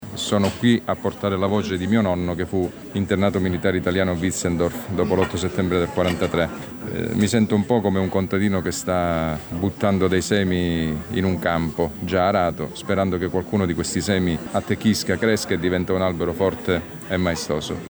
GIORNO DELLA MEMORIA A PRAIA A MARE CON GLI STUDENTI DELL’ALTO TIRRENO